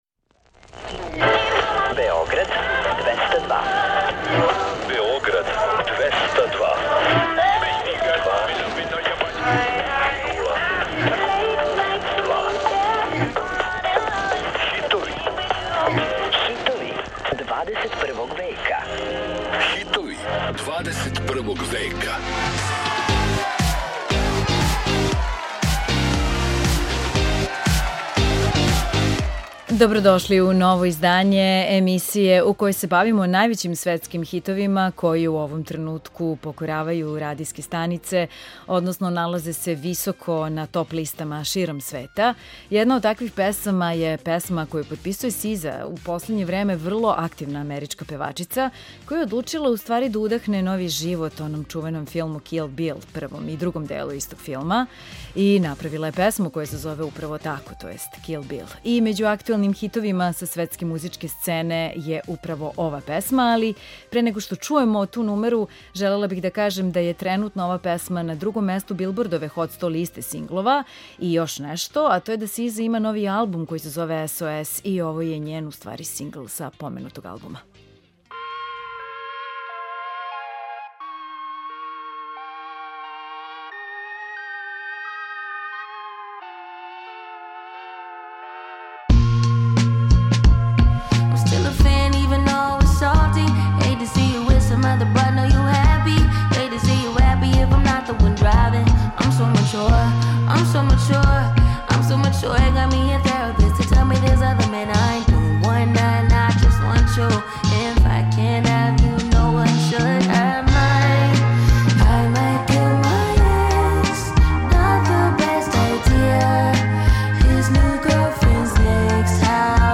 Čućete pesme koje se nalaze na vrhovima svetskih top lista.